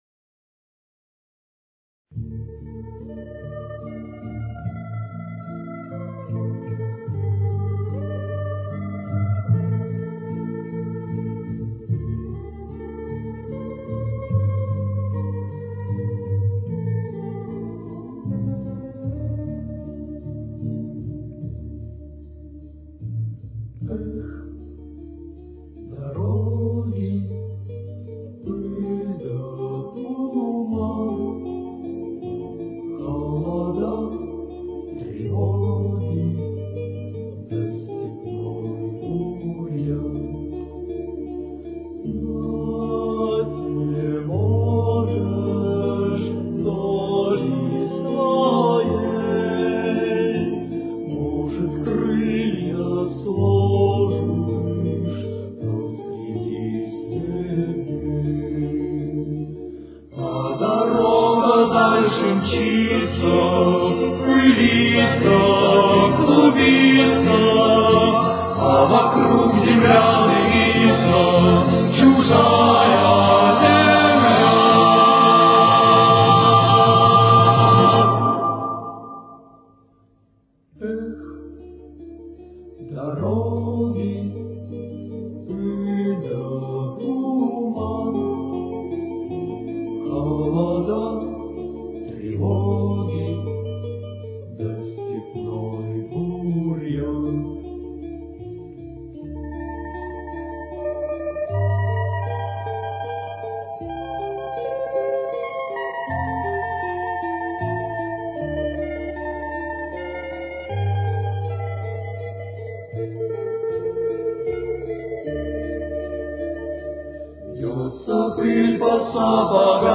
Ре минор. Темп: 79.